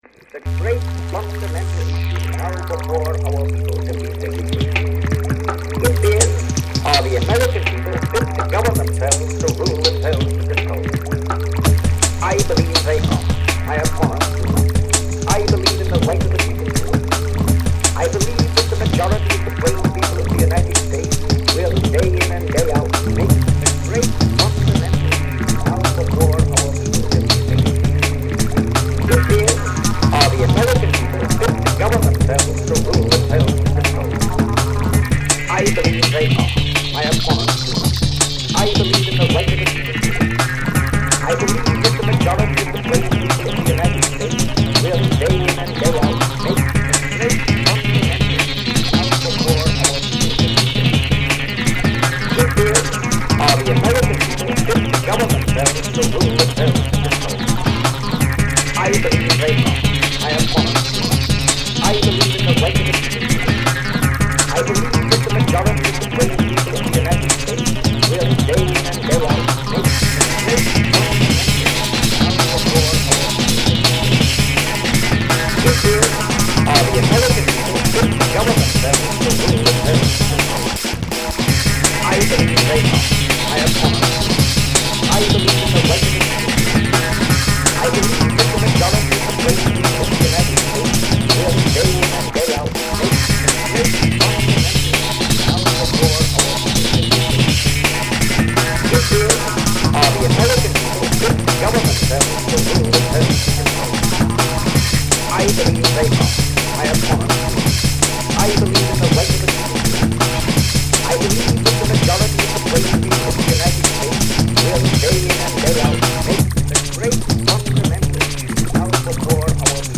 The ABC is my electronic music project. Between March of 2000 and January of 2001 I created seven songs using Mixman Studio Pro.
Our Submerged President - This was my first creation. Synths ascending and descending, drums banging, and Teddy Roosevelt. I would call it Electronica, while others would say it sound like Pink Floyd.